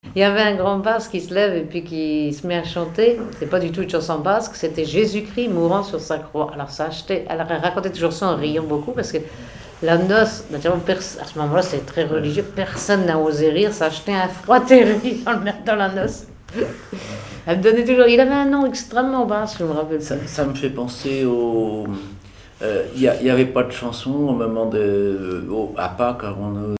chanteur(s), chant, chanson, chansonnette
Miquelon-Langlade